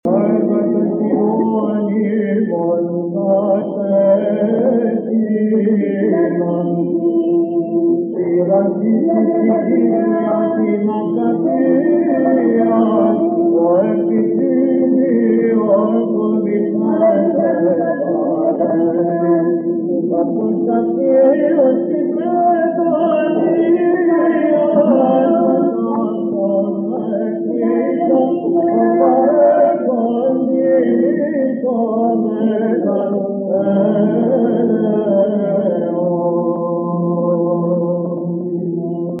(ἠχογρ. Κυρ. Βαΐων ἑσπέρας)